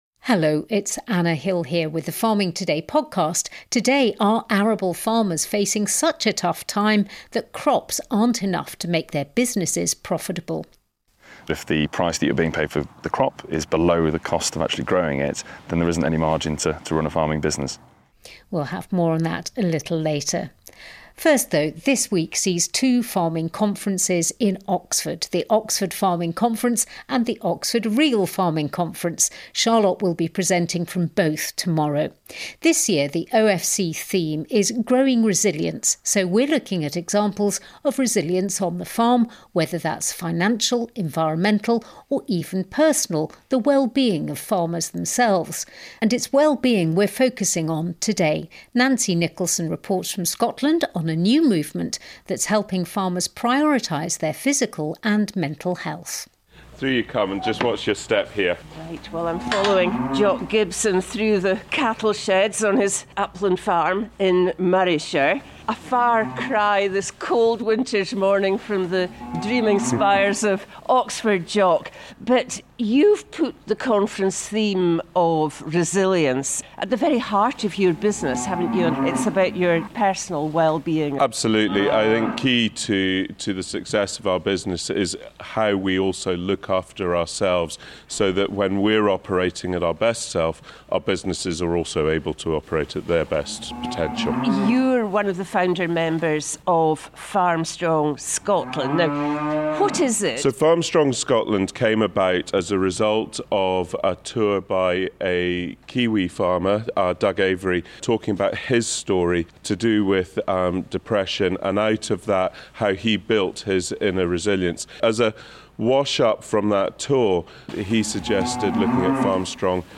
The programme explores how we can deliver renewable energy without sacrificing Norfolk’s best farmland and landscapes, featuring voices, ideas and debate from the exhibition itself, including our trustees.